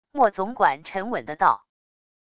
墨总管沉稳的道wav音频